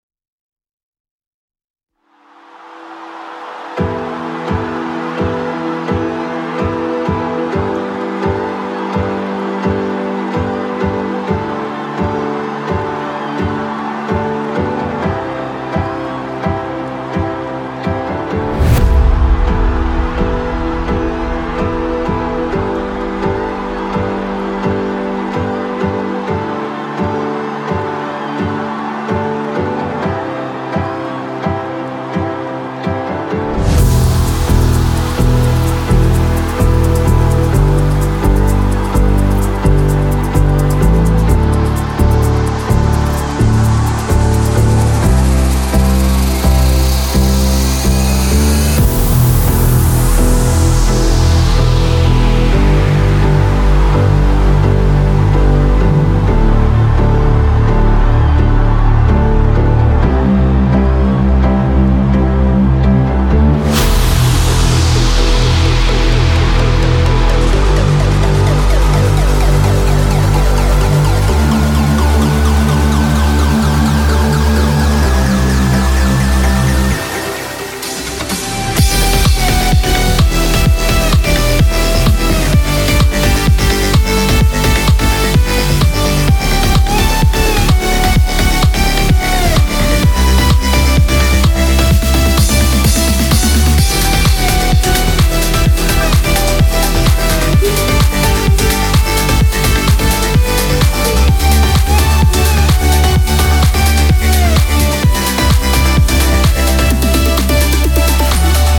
את הפסנתר בהתחלה אתה עשית?
רמיקס
השקעתי רק בדרופ(כל השאר זה בשביל שיהיה משו’